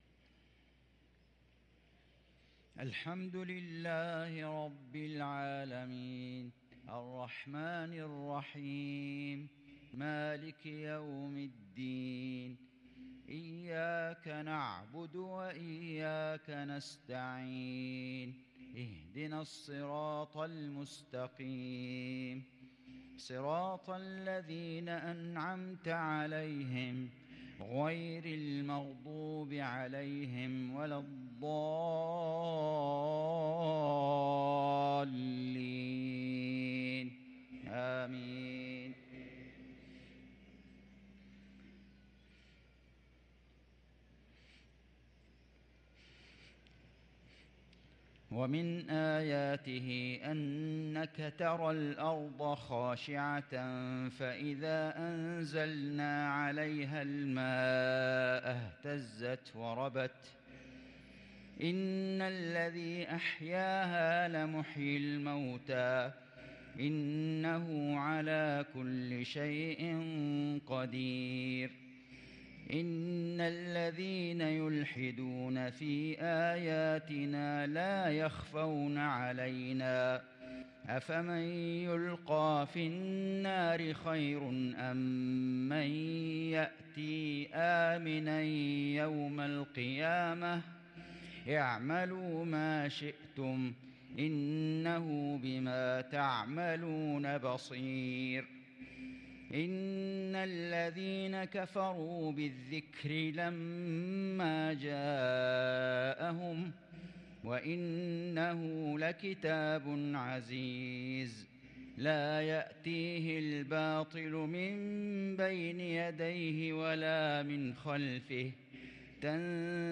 صلاة المغرب
تِلَاوَات الْحَرَمَيْن .